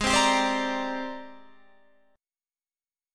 G#M#11 chord